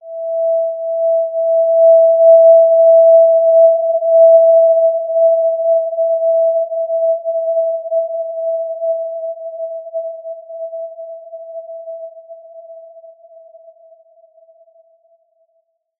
Basic-Tone-E5-mf.wav